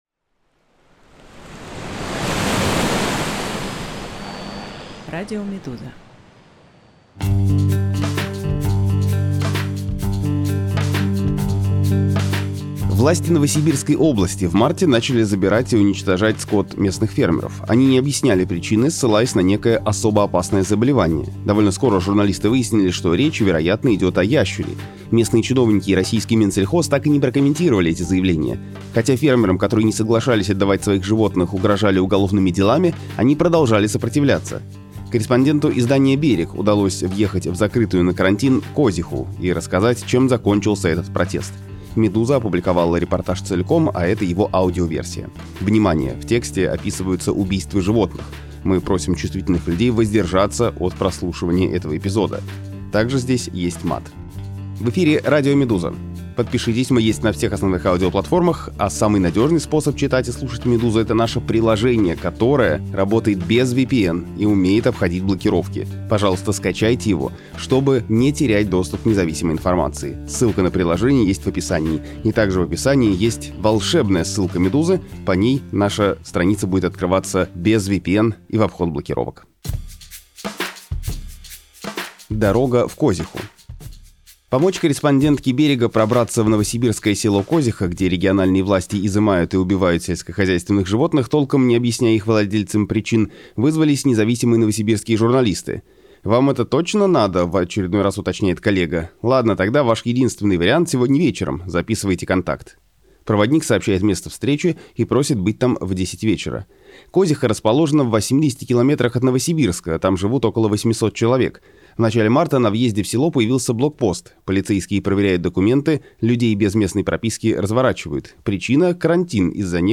Жители новосибирского села Козиха отказались отдавать скот властям, но их заставили. Репортаж о противостоянии фермеров и государства